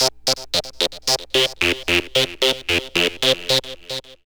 Track 15 - Synth 01.wav